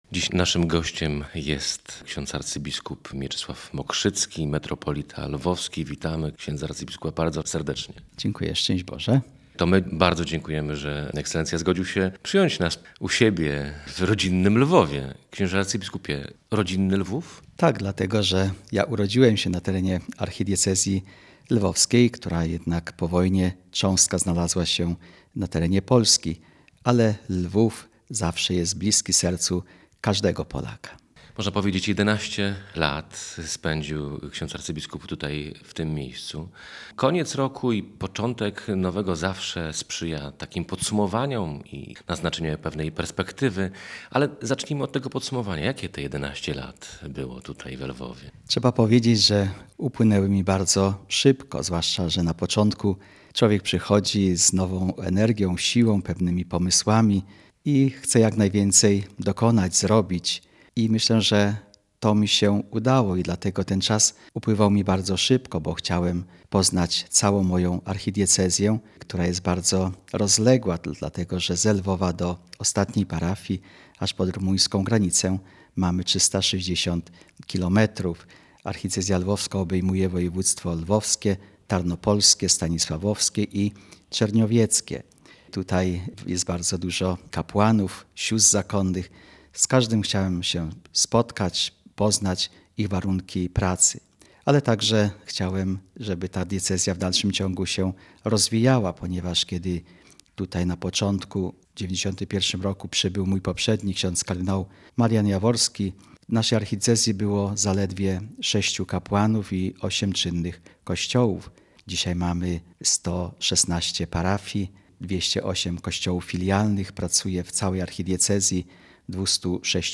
Gościem Dnia Radia Gdańsk był Mieczysław Mokrzycki - arcybiskup metropolita lwowski.